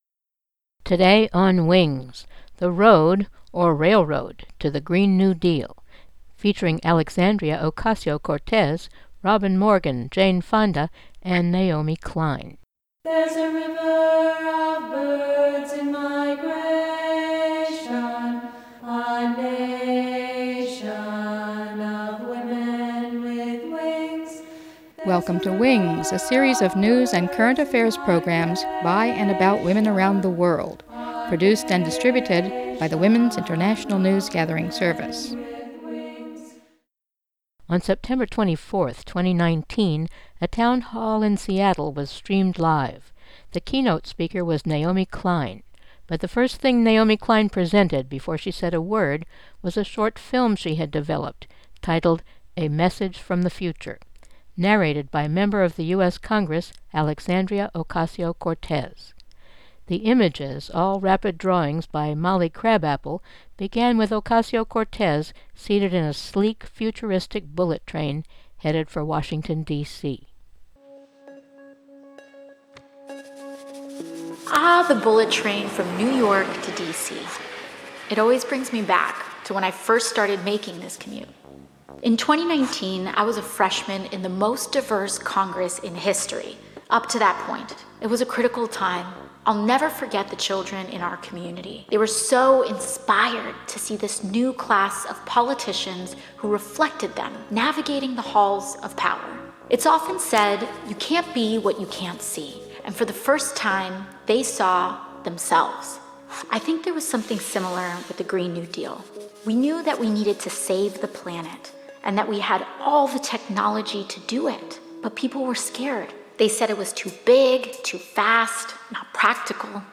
Sources: Ocasio-Cortez film and Naomi Klein talk in Seattle Town Hall